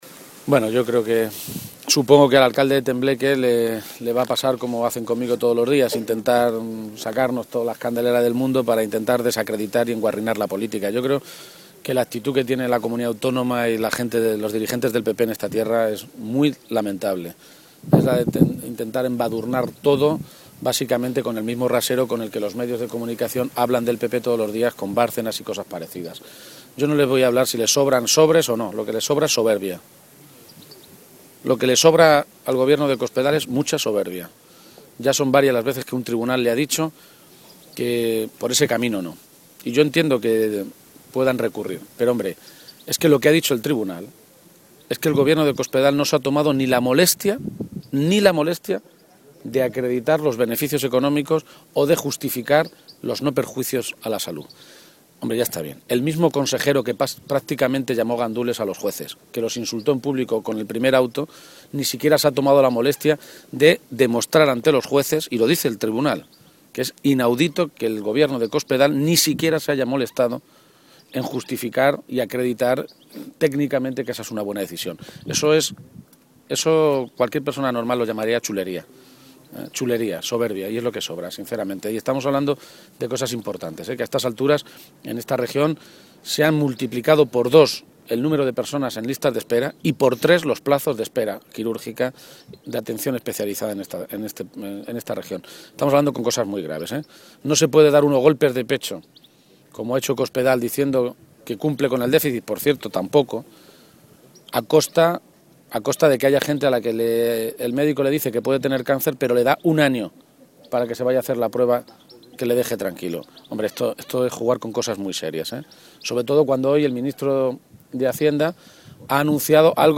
García-Page se pronunciaba de esta manera, en Toledo, a preguntas de los medios de comunicación, en referencia a unas manifestaciones de Montoro en el Congreso, esta mañana, en las que ha asegurado que, si Bruselas se lo permite, renegociará con las autonomías el calendario de cumplimiento de los objetivos de déficit para este año.
Cortes de audio de la rueda de prensa